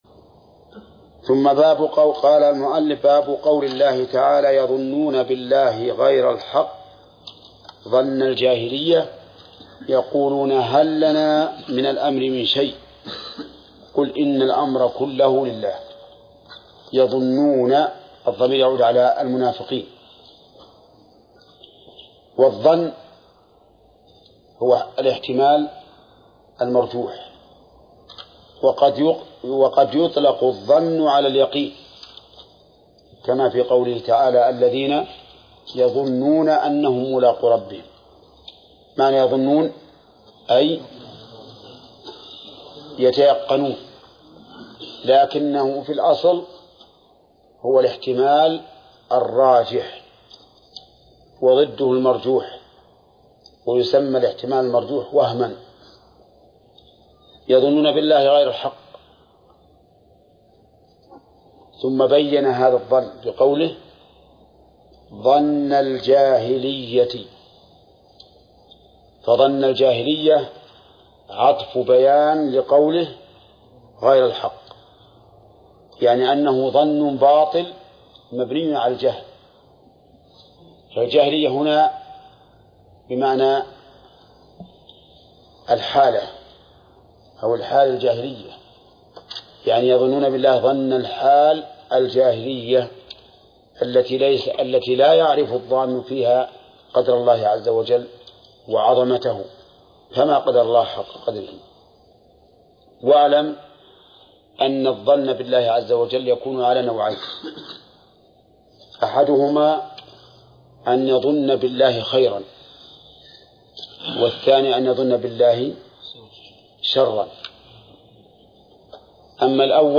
درس (48) / المجلد الثاني : من صفحة: (382)، قوله: (باب قوله تعالى: {يظنون بالله غير الحق ..} ).، إلى صفحة: (403)، قوله: (مراتب القدر: ..).